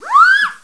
Fischio sirena
Suono di sirena ad aria. Fischio sirena.
Effetto sonoro - Fischio sirena